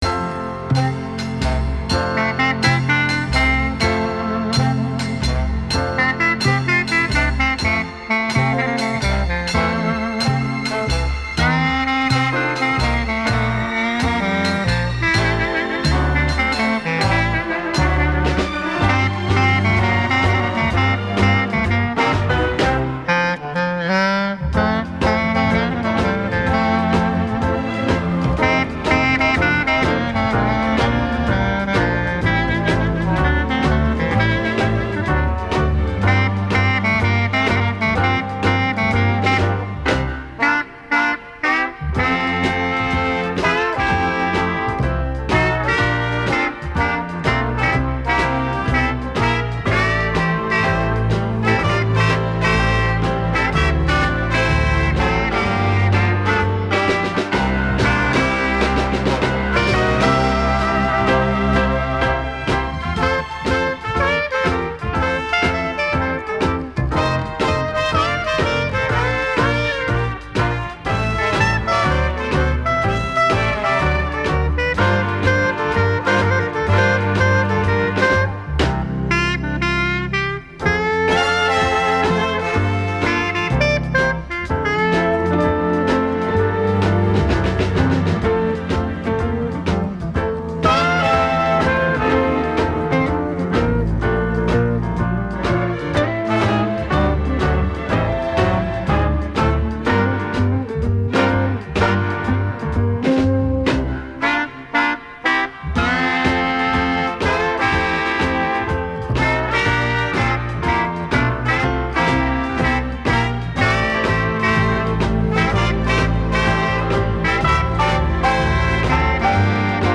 Genre:Easy Listening, Instrumental